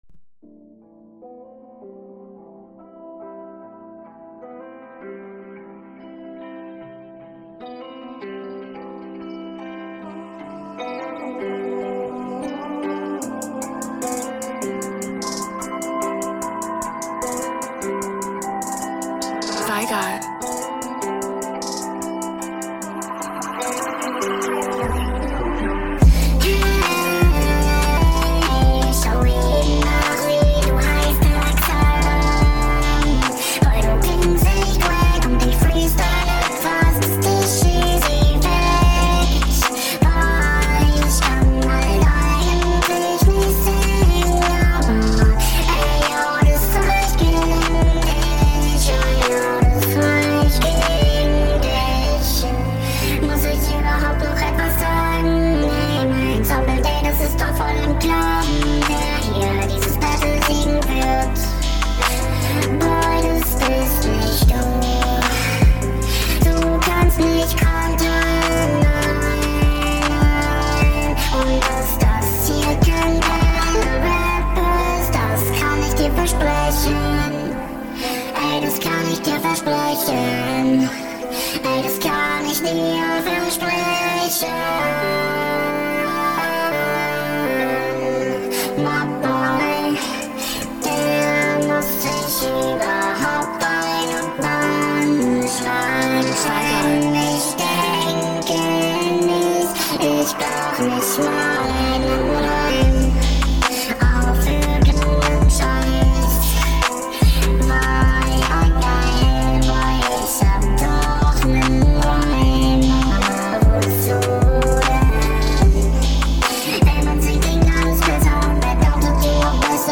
Ich glaube wenn du den Stimmverzerrer nicht benutzten würdest, würde sich alles voll cool anhören …